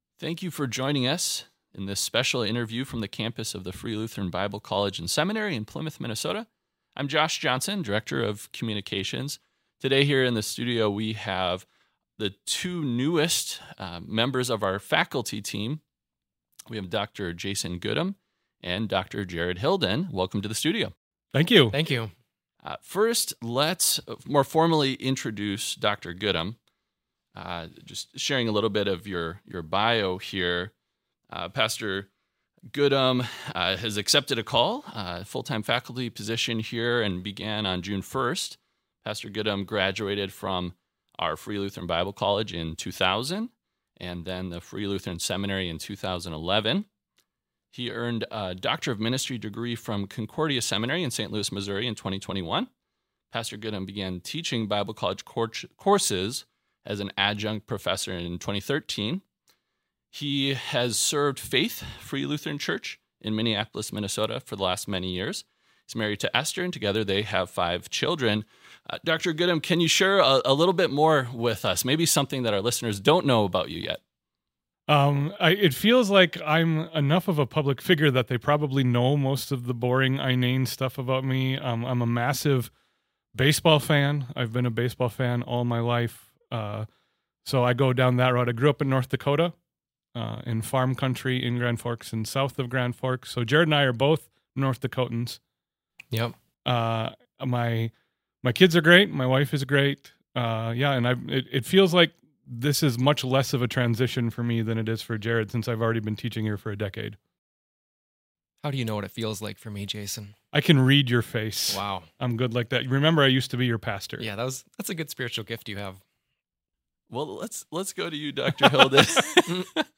New Faculty Interview